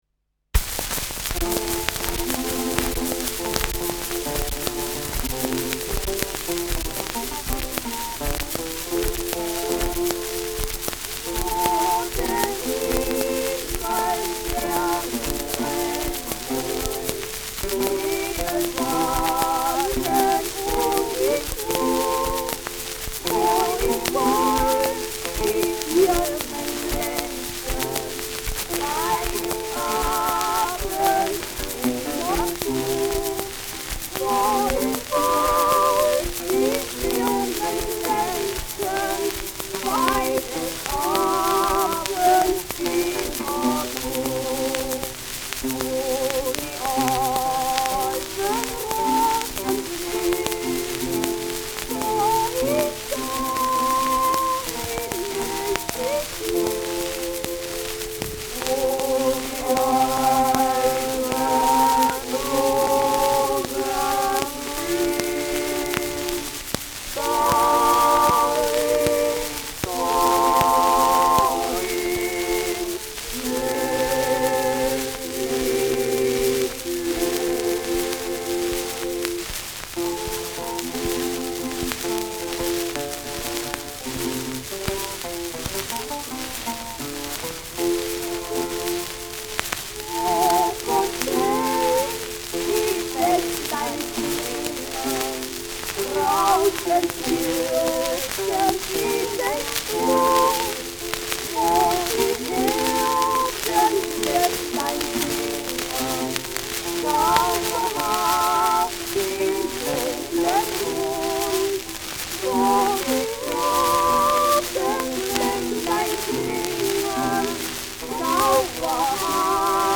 Schellackplatte
präsentes Rauschen : starkes Knistern : durchgehend präsent bis starkes Nadelgeräusch : abgespielt : leiert
Jahodas Glückskinder, Salzburg (Interpretation)
[Berlin] (Aufnahmeort)